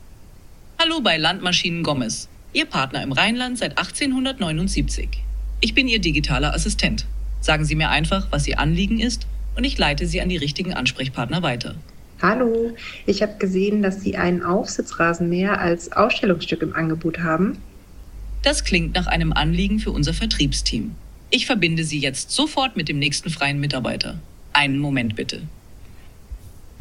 Aber wie klingt der KI-Bot am Telefon? hier ein paar Praxisbeispiele